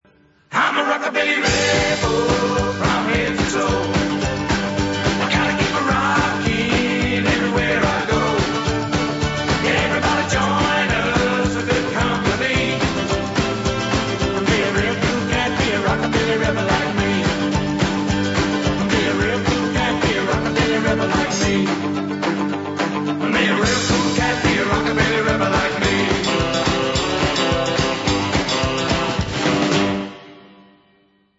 steel guitar, mandolin, harmonica & vocals
rhythm guitar, accoustic guitar & vocals
bass guitar, double bass & vocals
drums, percussion & vocals